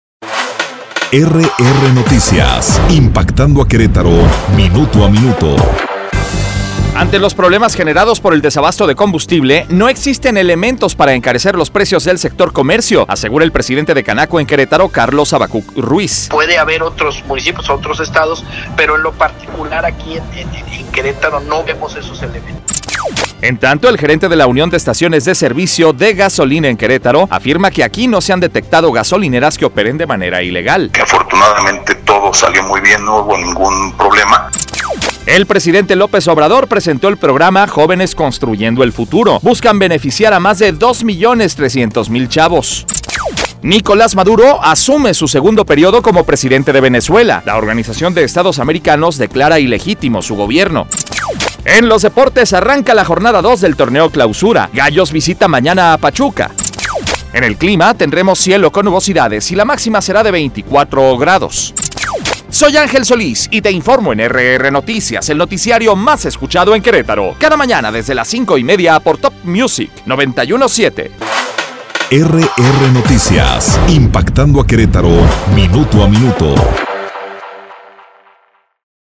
Resumen Informativo 11 de enero 2019